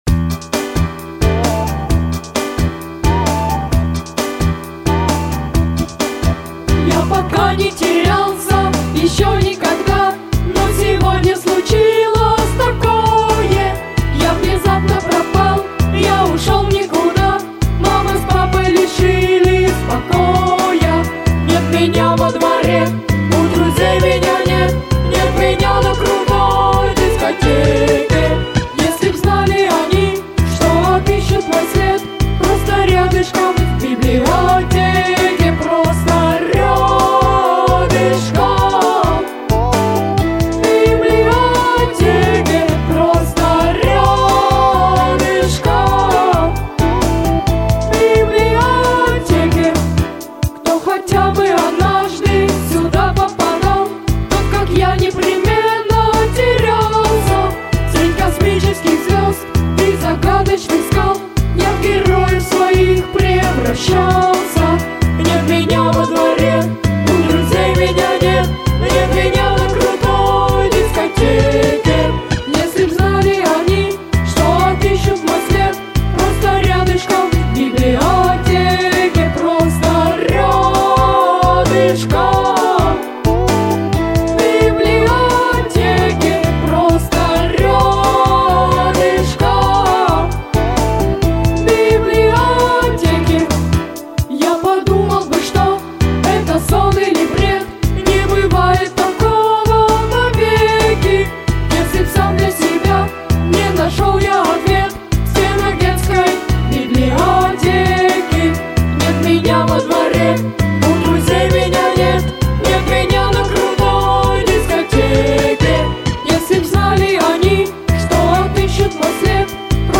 • Категория: Детские песни / Песни про маму